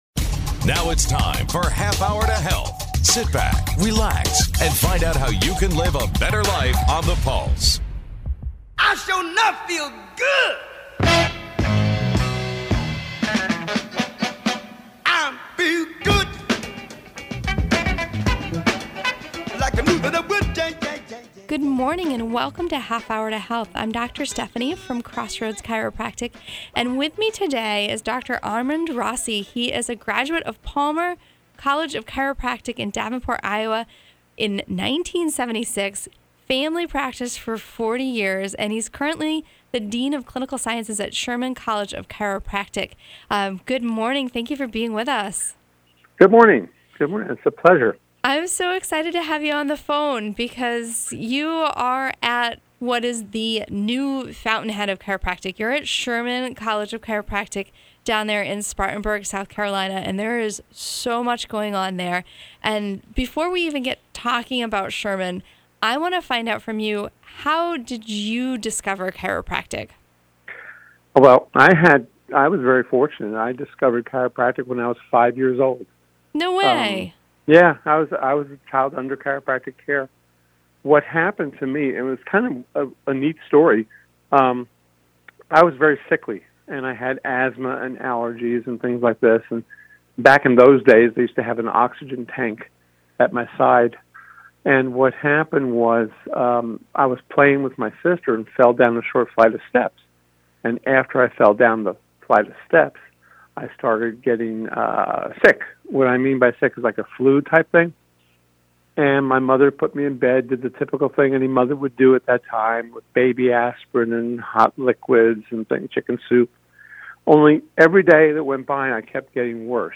WTPL is a news, talk, and sports radio station, located in Manchester, NH.